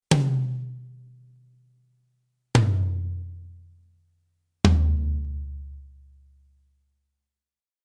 Zweifache Mikrofonierung von Toms
Bei aufwändigeren Produktionen wird gelegentlich auch die Resonanzfellseite der Toms mit Mikrofonen versehen.
Der von diesen Mikrofonen aufgezeichnete Klang zeichnet sich durch einen verstärkten Sustain-Anteil mit deutlichem Oberton-Anteil und wesentlich geringerem Anteil an Attack im Vergleich zum Klangbild der Schlagfellseite aus.